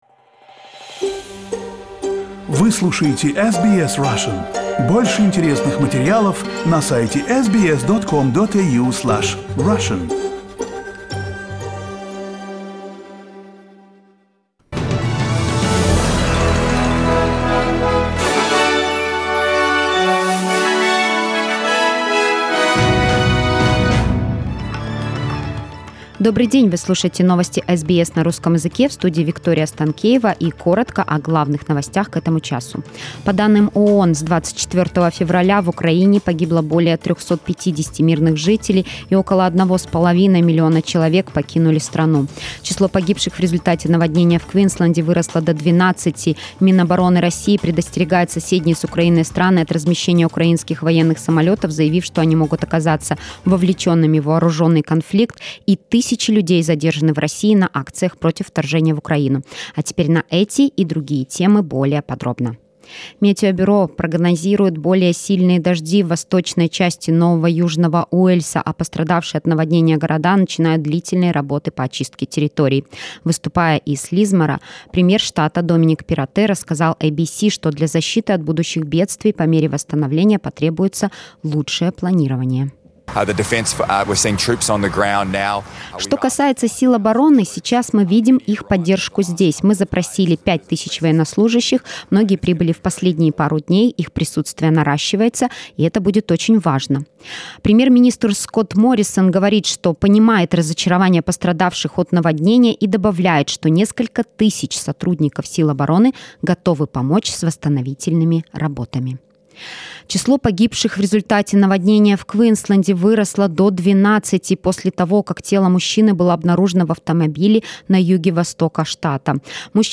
Listen to the latest news headlines in Australia from SBS Russian.